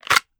Kydex Unholster 001.wav